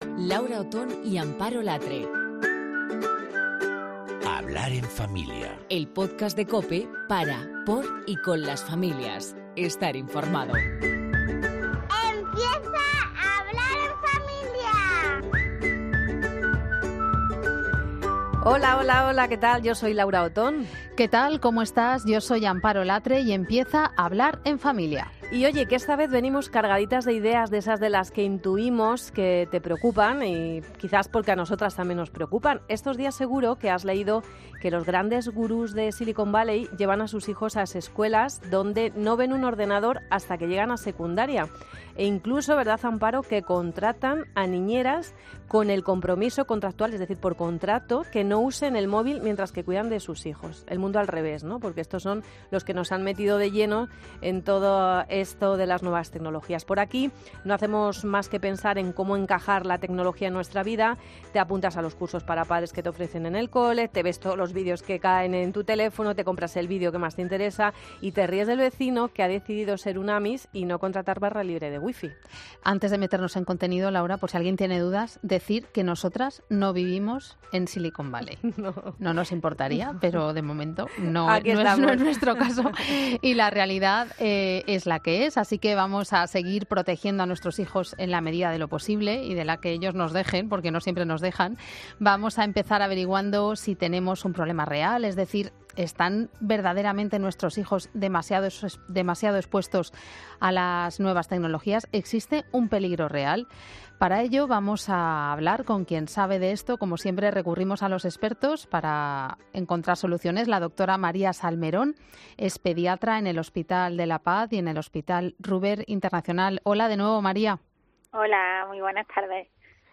Si quieres escuchar la entrevista completa dale al play porque te sorprenderás la cantidad de cosas que podemos hacer para prevenir.